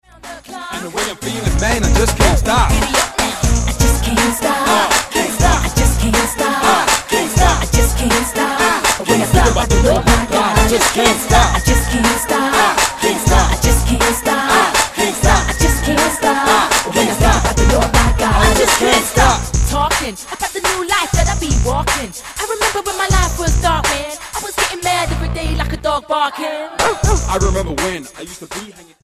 British urban gospel duo
Style: R&B